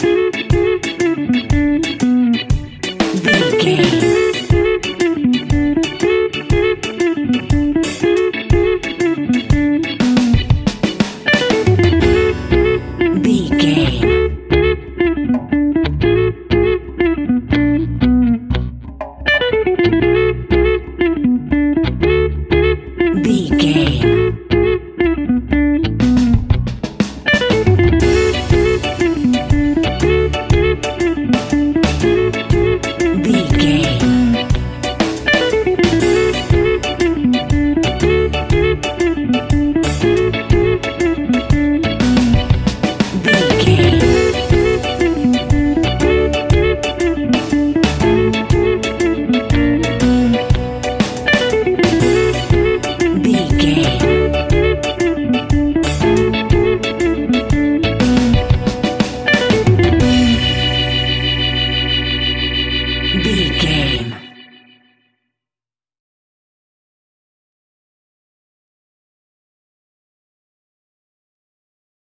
Aeolian/Minor
dub
reggae instrumentals
laid back
chilled
off beat
drums
skank guitar
hammond organ
percussion
horns